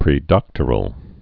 (prē-dŏktər-əl)